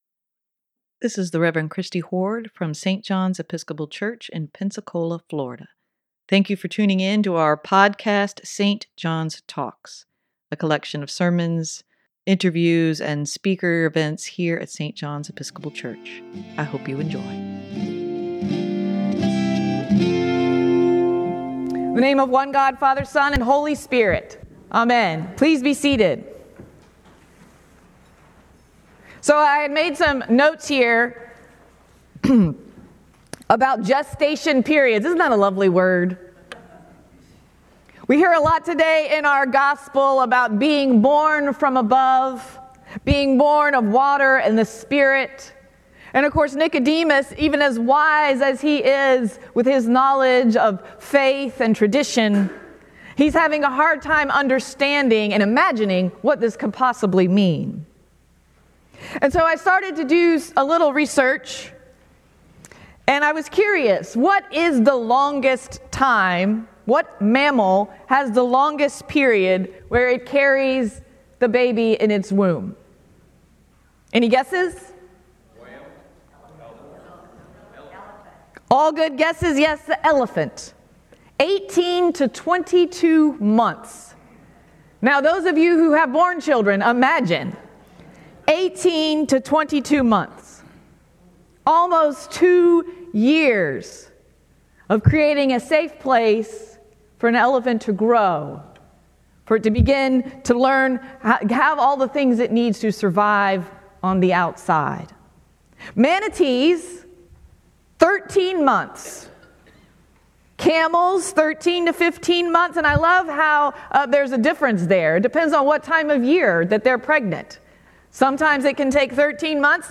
Sermon for March 5, 2023: Come Out Into the Light - St. John's Episcopal Church
sermon-3-5-23.mp3